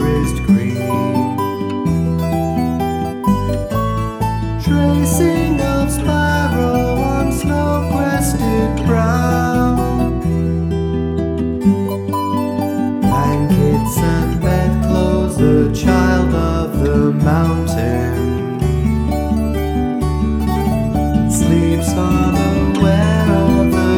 No Harmony Pop (1960s) 3:08 Buy £1.50